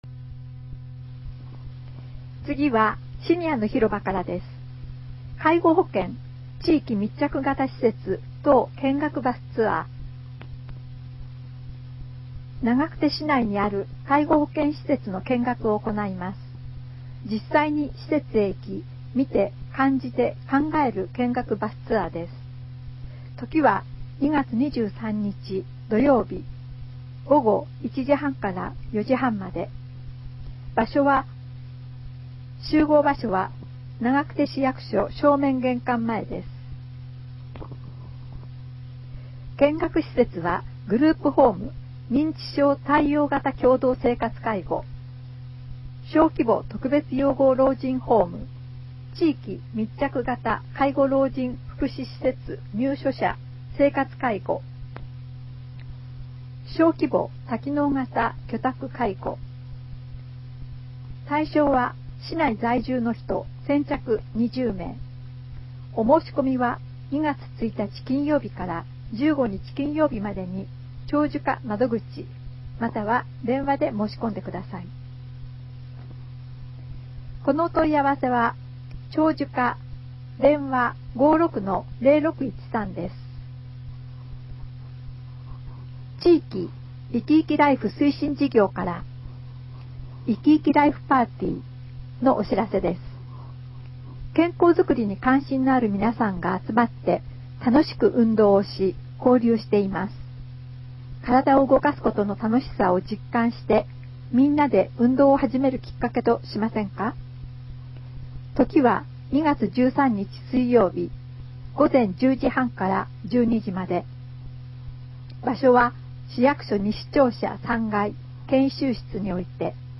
平成29年8月号から、ボランティア団体「愛eyeクラブ」の皆さんの協力により、広報ながくてを概要版として音声化して、ホームページ上で掲載しています。
音声ファイルは、カセットテープに吹き込んだものをMP3ファイルに変換したものです。そのため、多少の雑音が入っています。